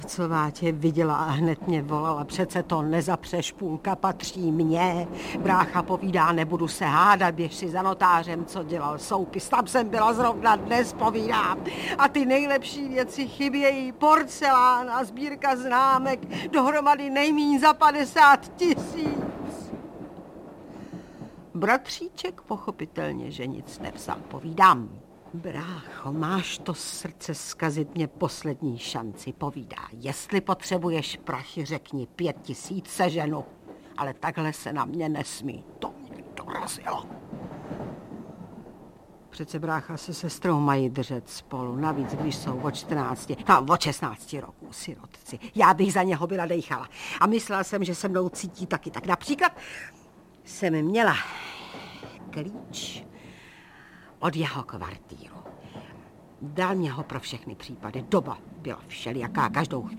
Audiobook
Read: Alena Vránová